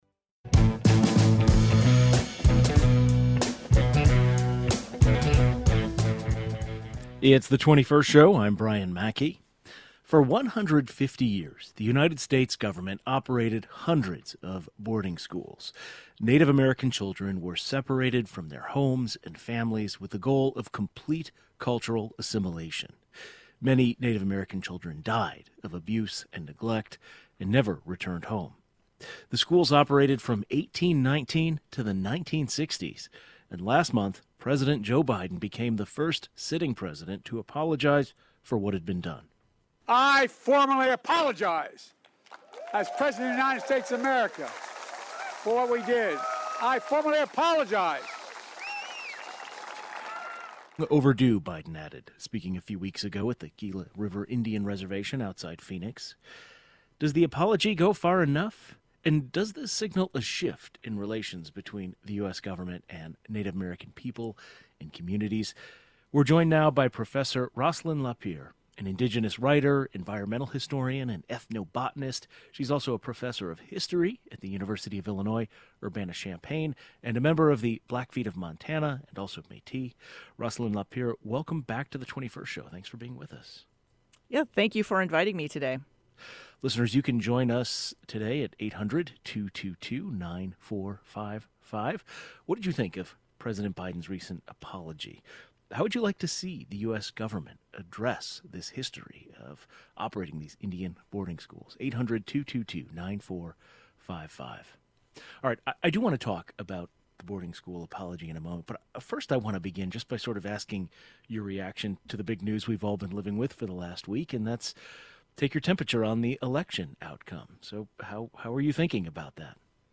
An indigenous writer, environmental historian and ethnobotanist shares her thoughts on the historic apology, disturbing details of what Native American children went